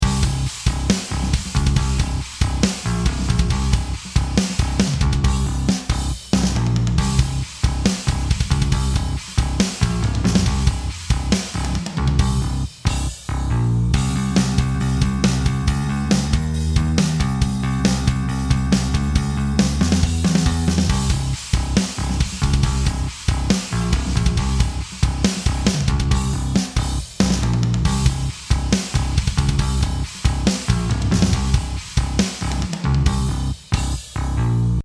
Here’s how the drums sound with no compression applied:
C165a-Drum-Bus-DRY.mp3